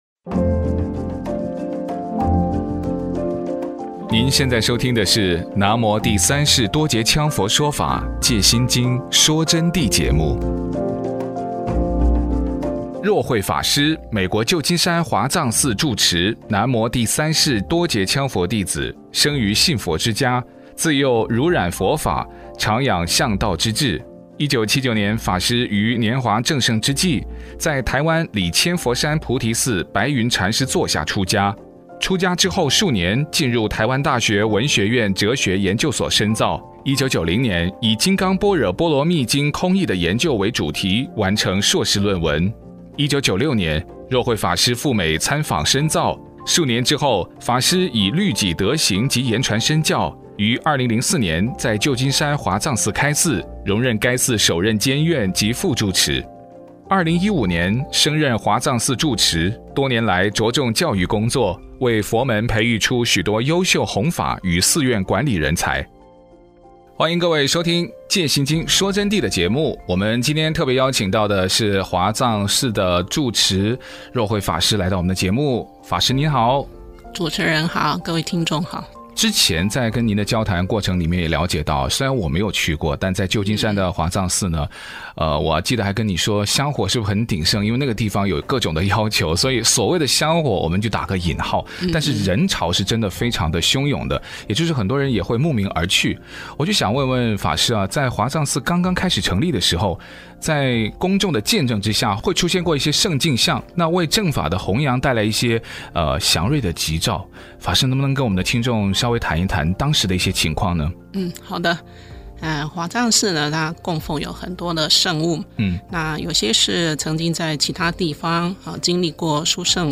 佛弟子访谈（十二）浅谈佛学、佛性、放生